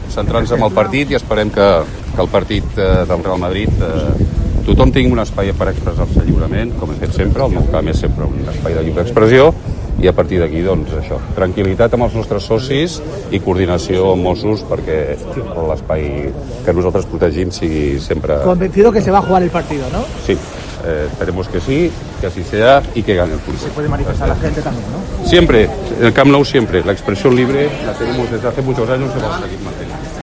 en declaraciones al micrófono